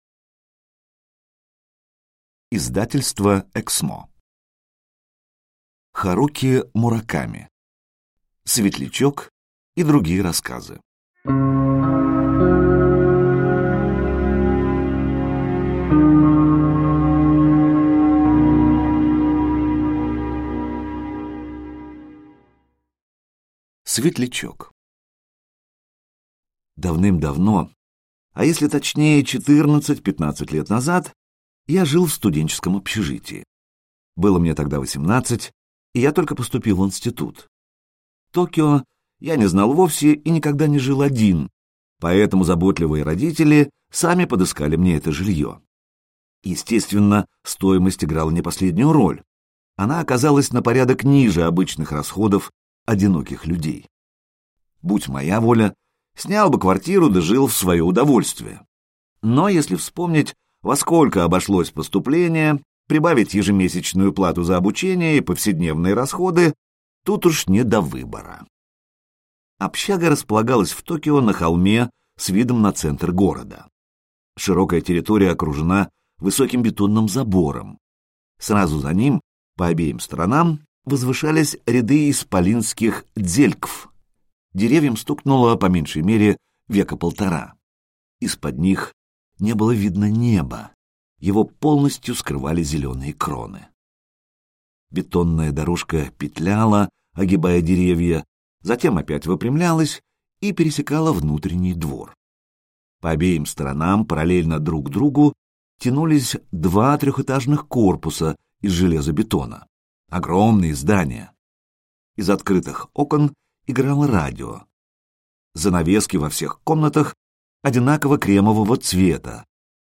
Аудиокнига Светлячок и другие рассказы (сборник) | Библиотека аудиокниг